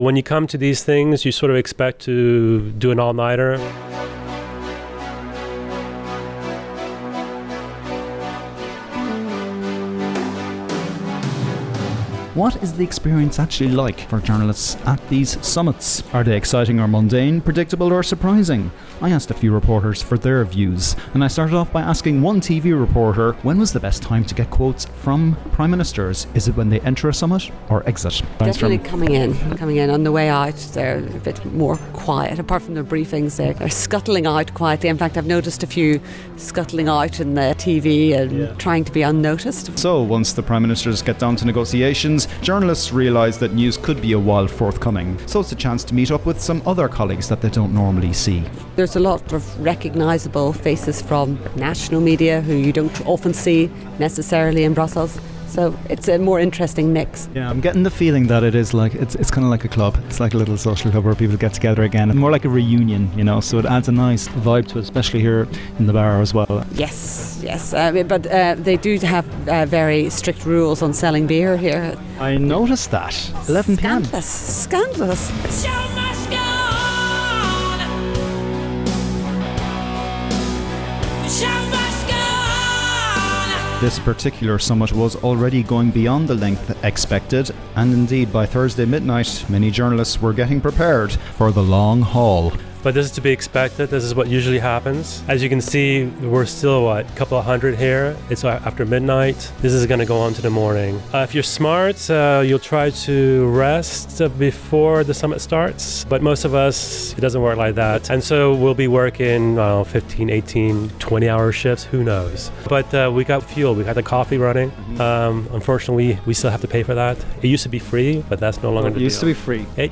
asked fellow journalists for their tips and experiences.